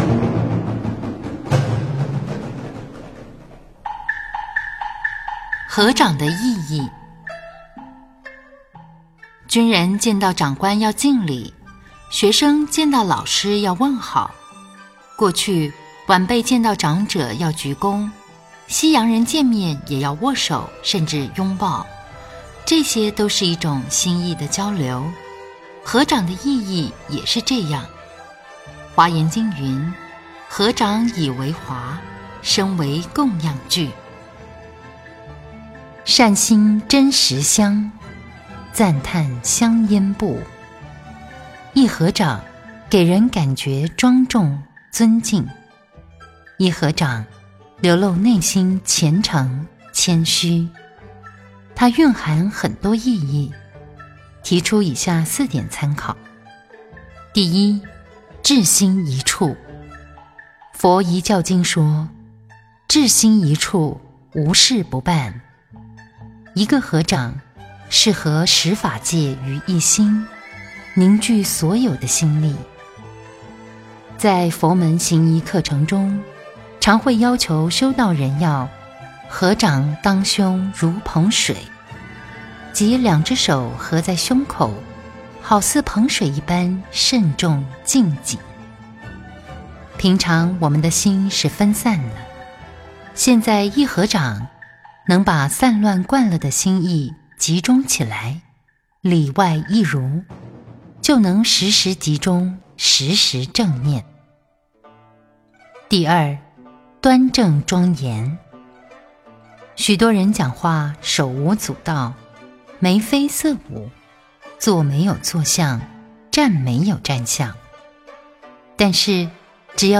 佛音 冥想 佛教音乐 返回列表 上一篇： 清净法身佛--天籁梵音 下一篇： 06.